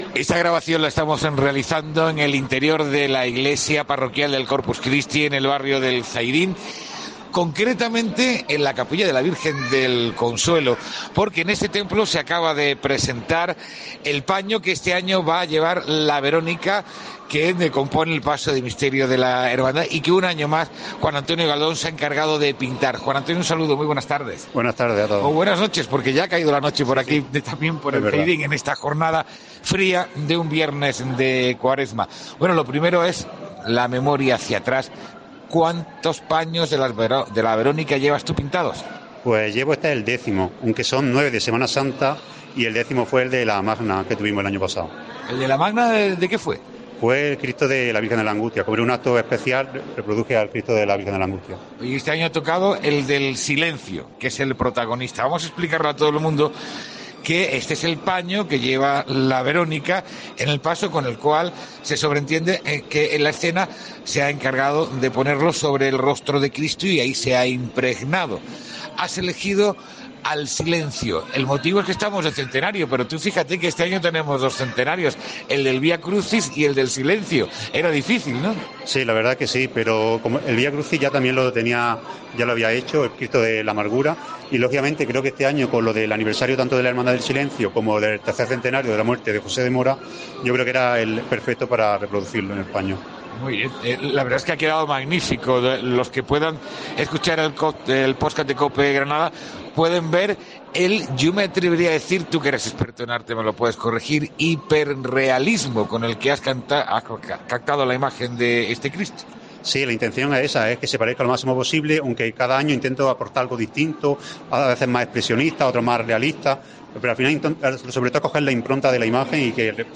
ENTREVISTA|| Conocemos el paño que llevará este Lunes Santo la Verónica de la Cofradía del Trabajo